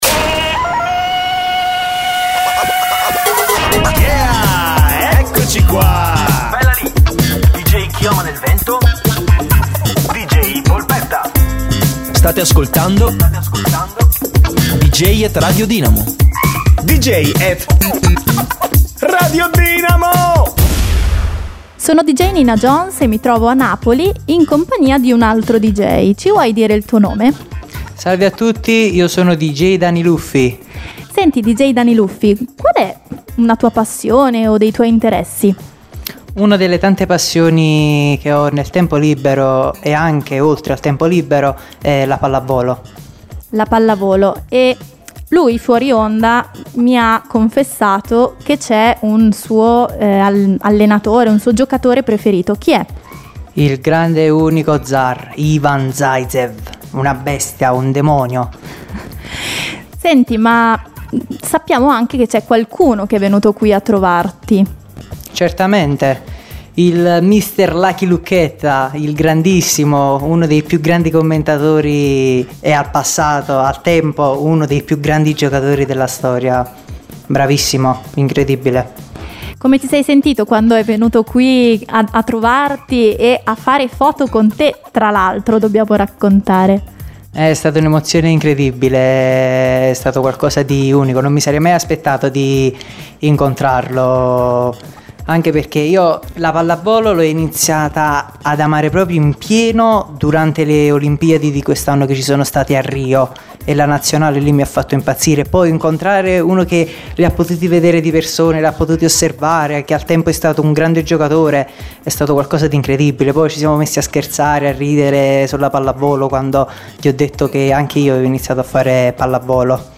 MINI INTERVISTA!
MININTERVISTA.mp3